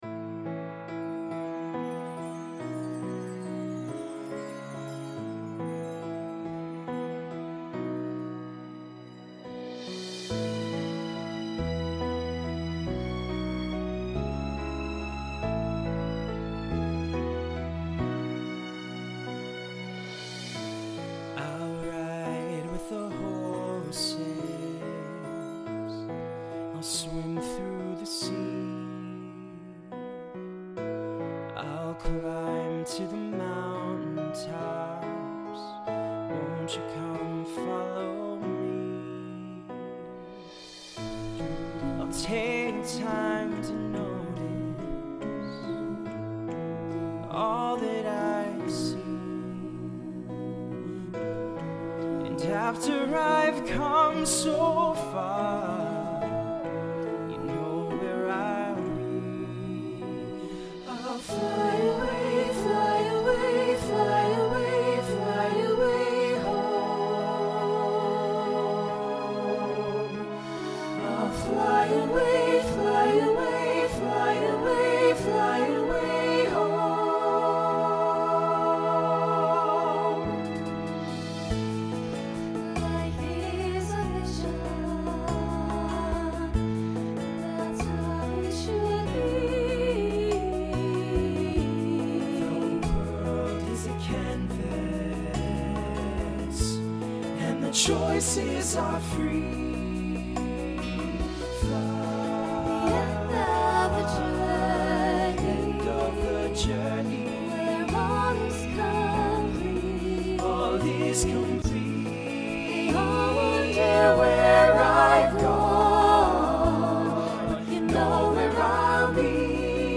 Voicing SATB Instrumental combo Genre Pop/Dance
2010s Show Function Ballad